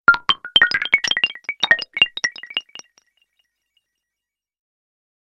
nokia-lumia-broken-tone_24526.mp3